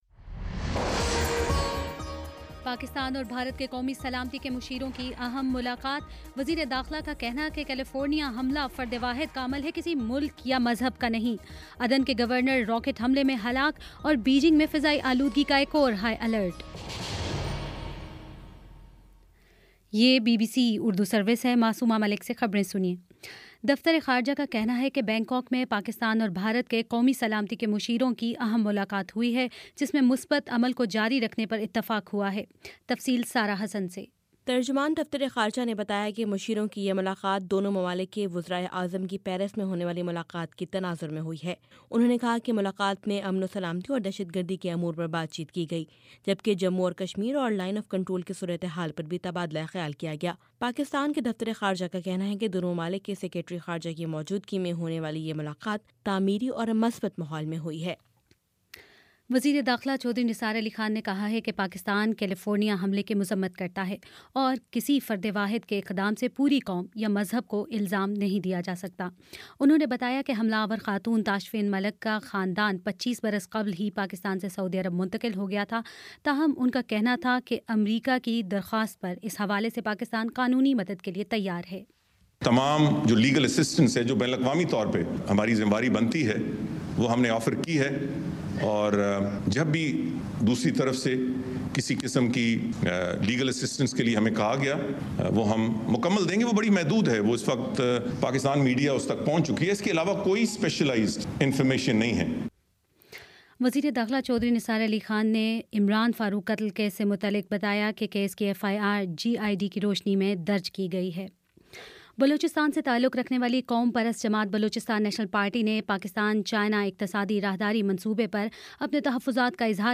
دسمبر 06 : شام چھ بجے کا نیوز بُلیٹن